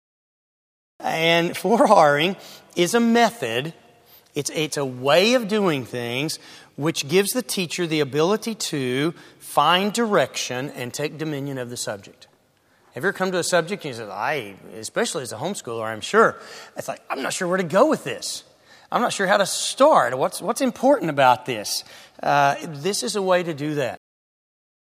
This seminar will explain the why behind 4-Ring and then demonstrate a step by step process on how to 4-R any subject in the curriculum, at any grade level.